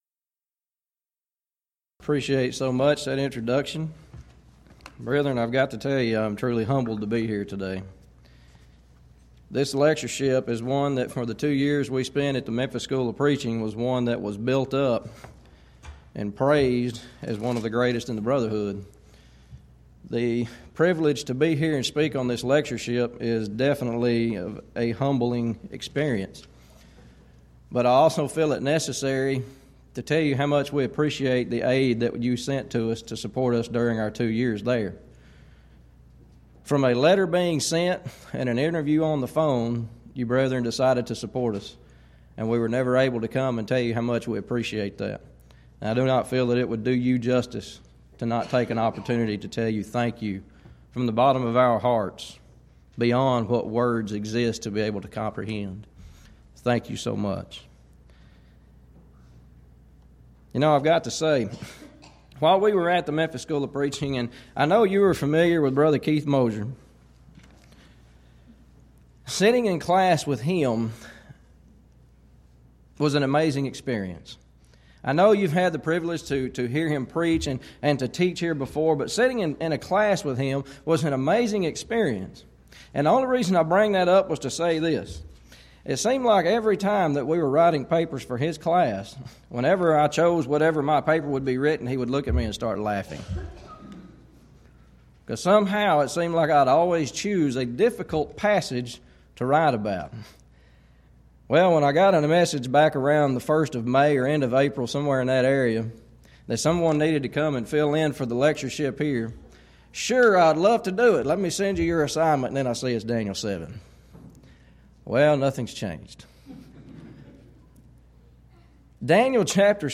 Event: 11th Annual Schertz Lectures
lecture